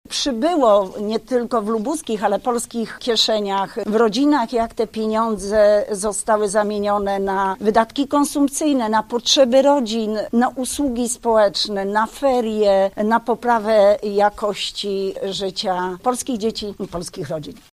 O szczegółach mówi minister rodziny, pracy i polityki społecznej, Elżbieta Rafalska: